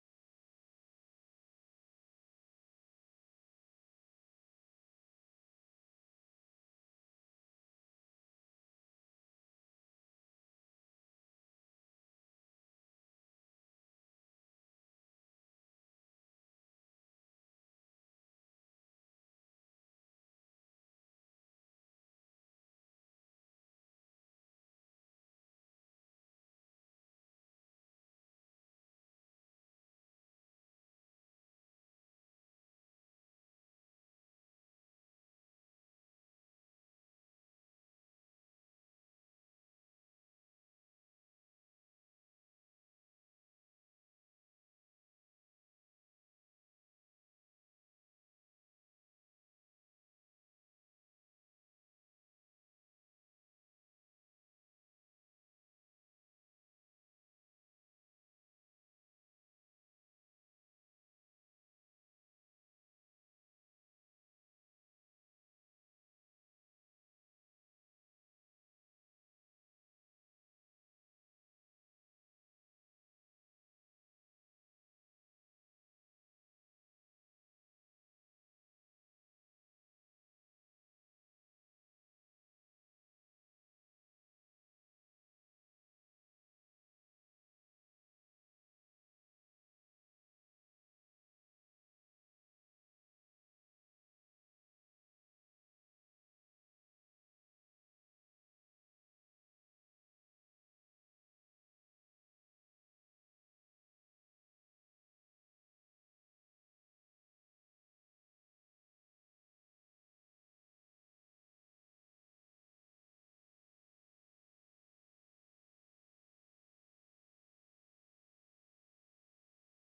Before Panel A26 Justice Pickering presiding Appearances